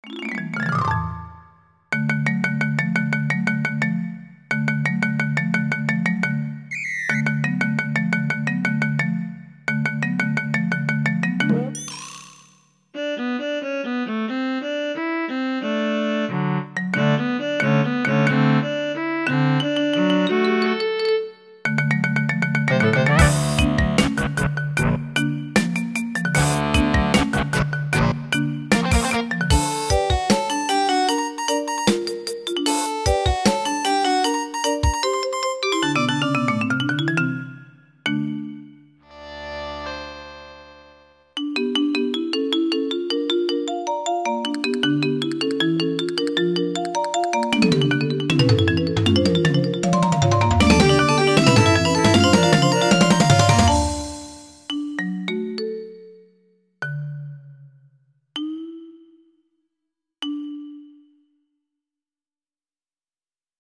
From the Diary's 2004 April Fools' Day MIDI Competition.